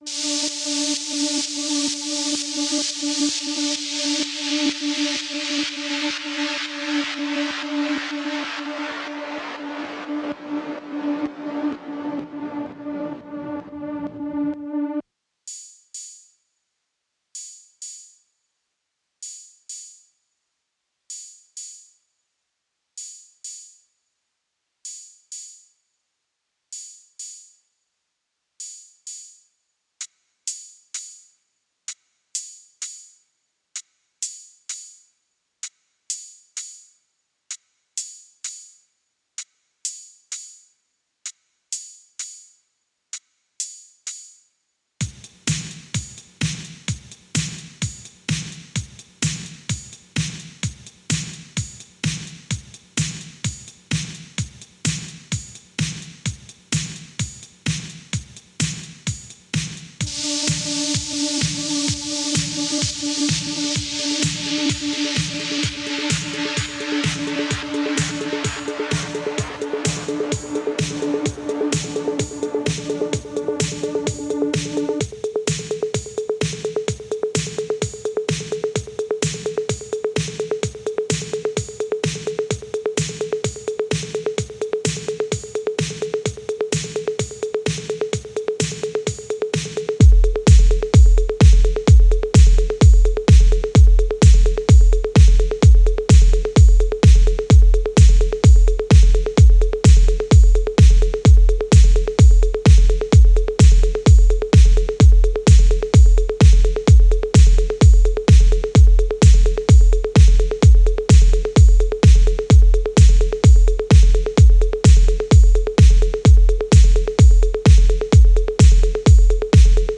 Cathédrale Notre-Dame de la Paix de N'Djaména, Tchad